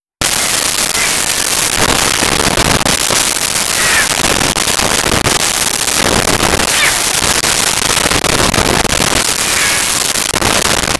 Cat Loud Meows
Cat Loud Meows.wav